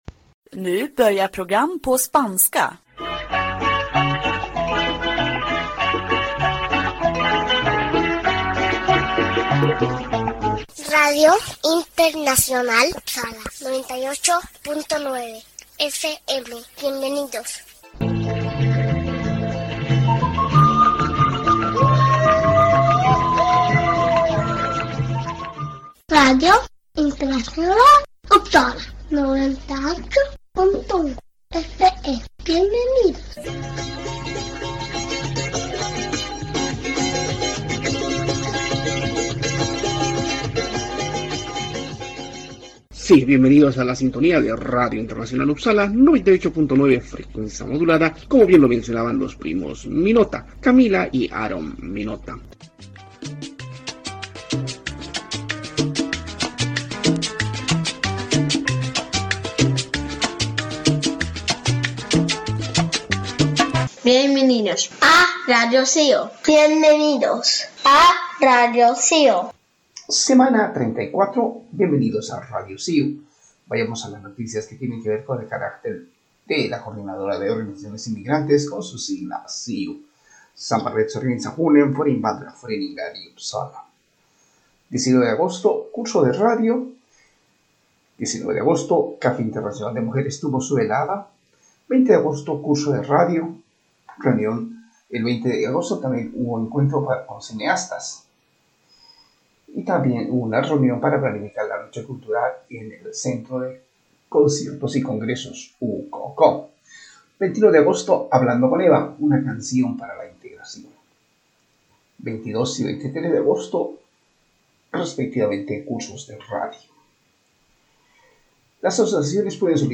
Sí, radio de cercanías en Uppsala se emite domingo a domingo a horas 18:30. La asociación de amistad Suecia-Latinoamérica SANKHAYU está detrás de todo este trabajo informativo,que fundamentalmente enfoca a noticias que tienen que ver con el acontecer de los inmigrantes en Uppsala.